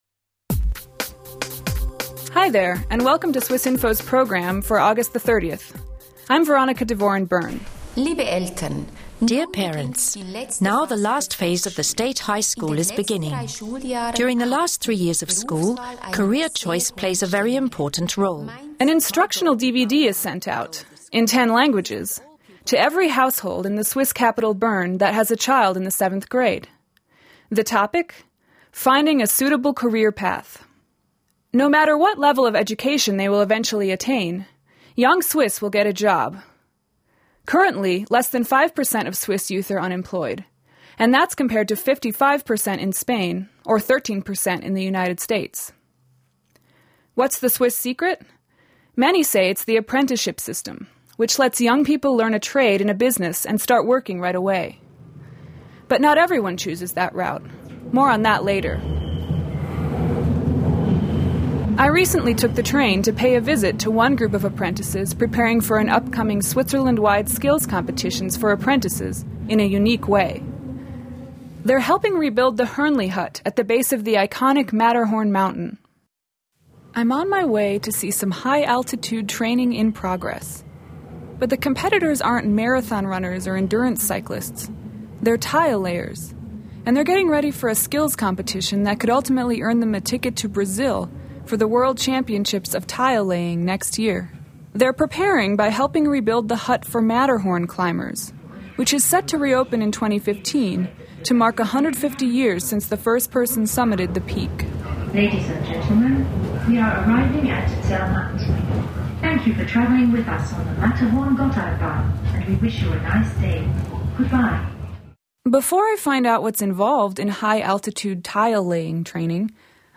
How has the youth unemployment rate stayed so low in Switzerland? From the top of the Matterhorn to the streets of Bern, young Swiss weigh in on career choices and options.